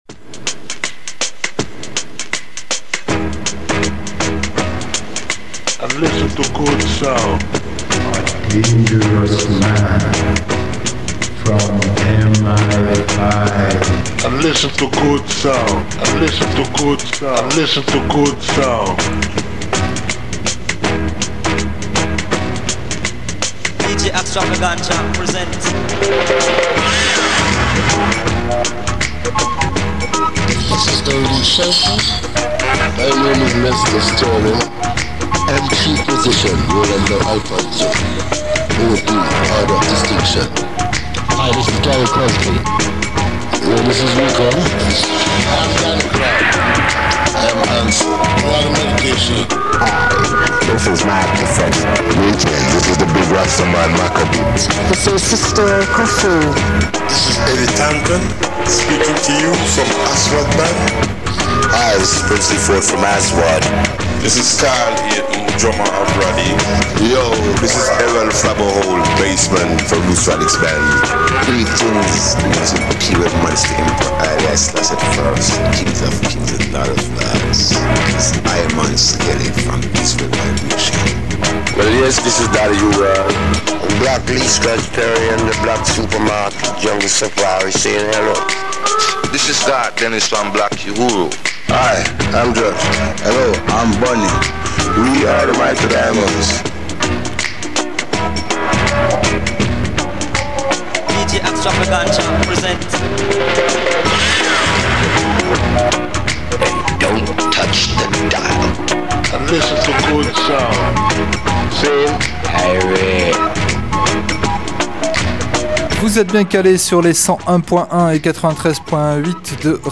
Programmation musicale éclectique, multi-générationnelle, originale, parfois curieuse, alternative et/ou consensuelle, en tous les cas résolument à l’écart des grands réseaux commerciaux.